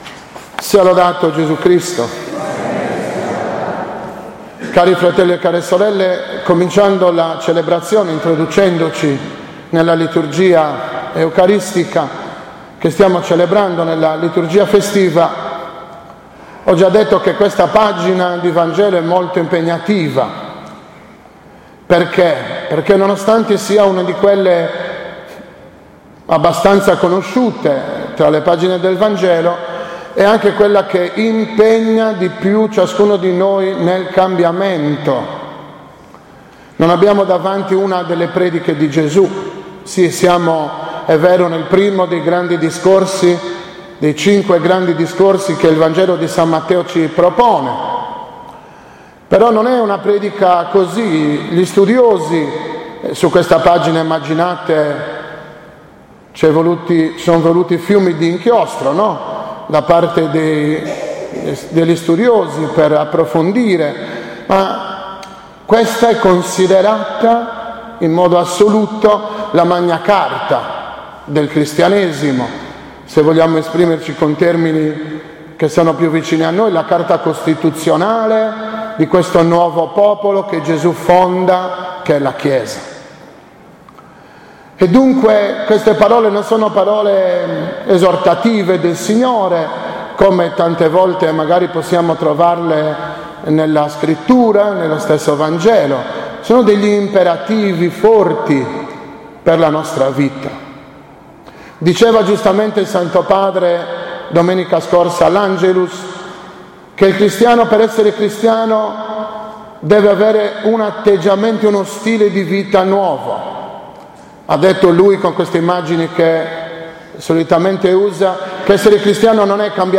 29.01.2017 – OMELIA DELLA IV DOMENICA DEL TEMPO ORDINARIO